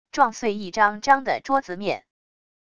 撞碎一张张的桌子面wav音频